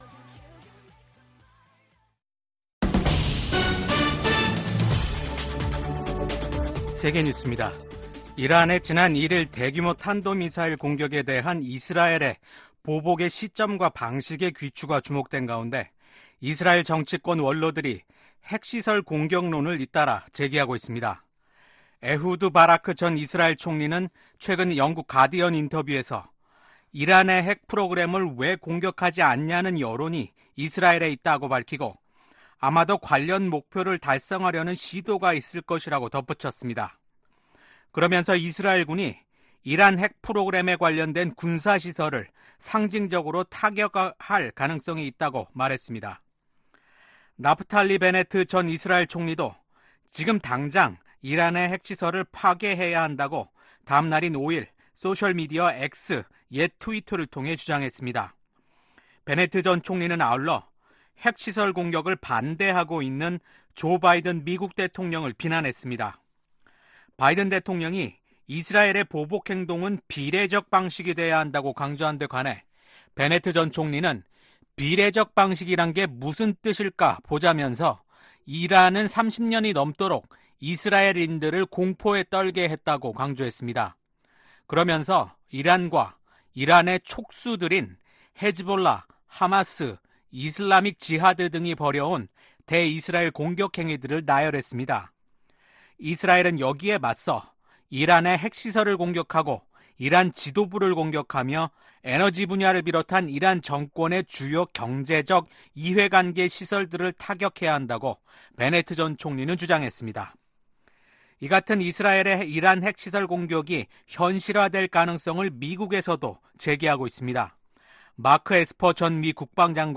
VOA 한국어 아침 뉴스 프로그램 '워싱턴 뉴스 광장' 2024년 10월 8일 방송입니다.